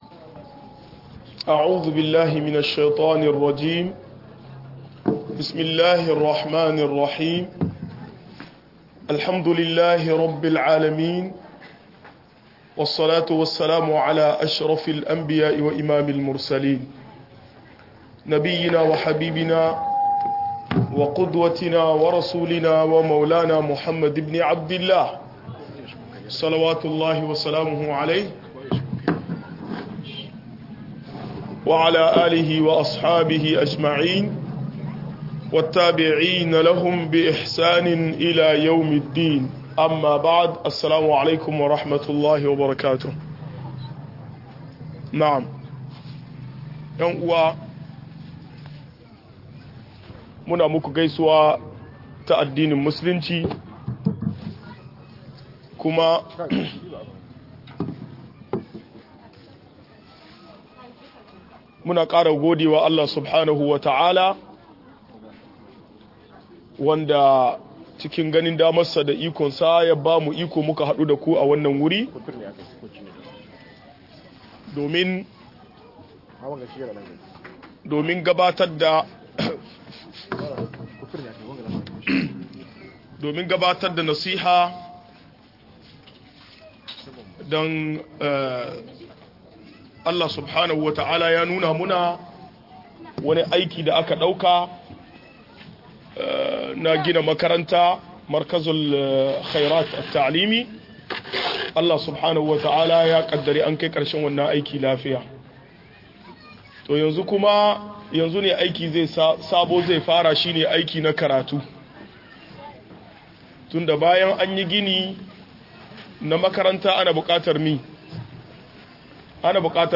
Ƙalubalen da mata ke fuskanta a yau - MUHADARA